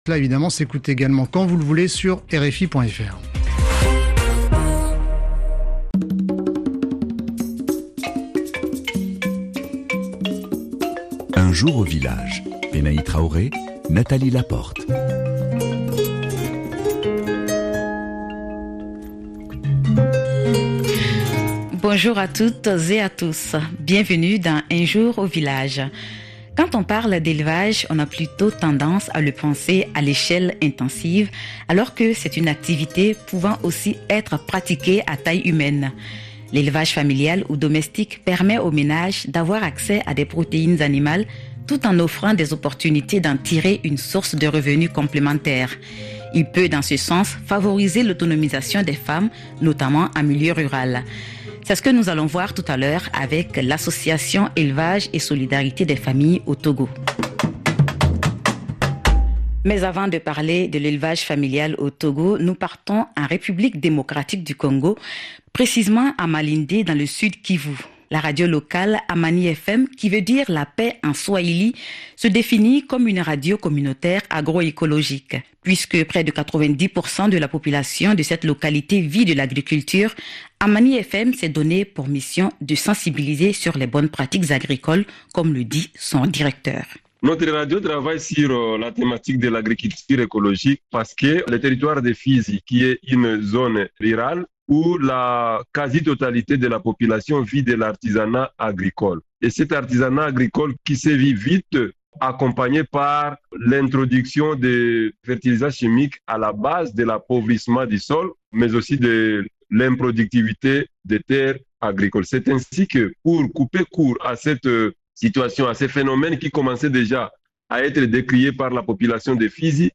Radio partenaire : Amani Fm en RDC.